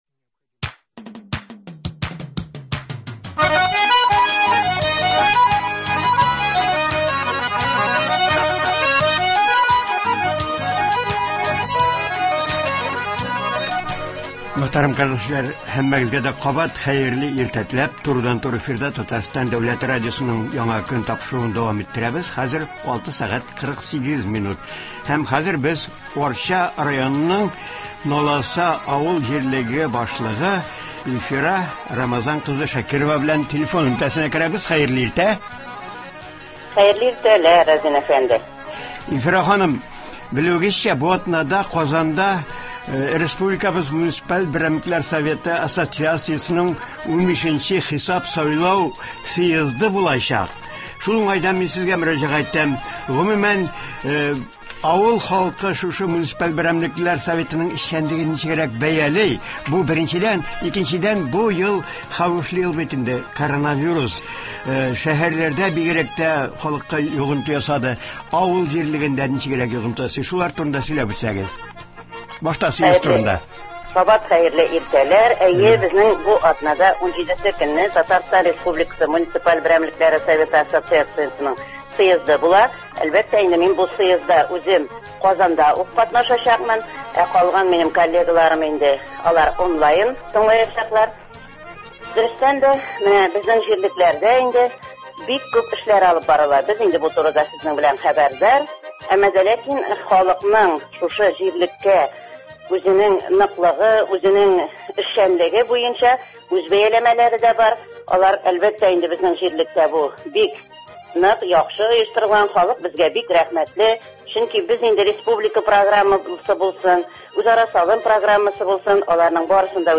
Бу атнада Муниципаль берәмлекләр Советының 15нче хисап сайлау съезды булачак. Шул уңайдан Арча районының Наласа авылы җирлеге башлыгы Илфира Шакирова Республикабызда 50гә якын Президент программасы гамәлдә булуы, үзара салымның авылны төзекләндерүгә аеруча зур өлеш кертүе турында сөйли, тыңлаучыларны якынлашып килүче Яңа ел белән котлый.